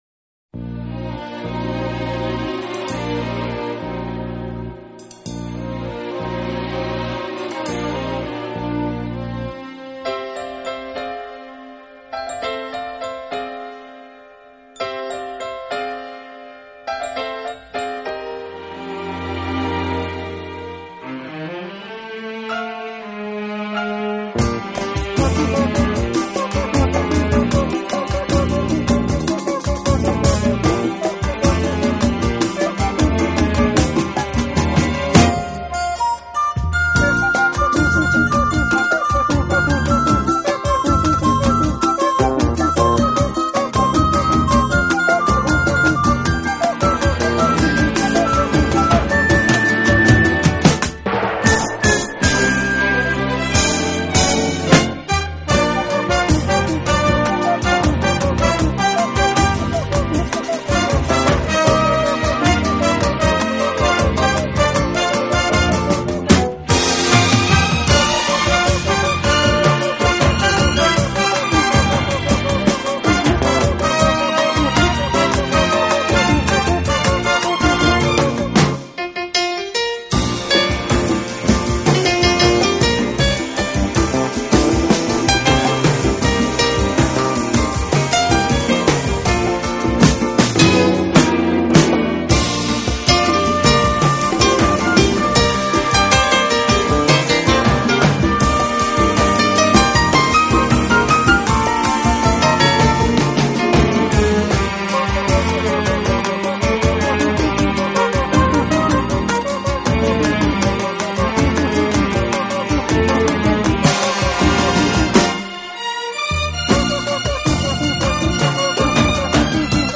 【顶级轻音乐】
他的音乐华丽而不滟俗
浪漫而不轻浮，粗旷之中带着细腻，热情之中蕴藏着宁静。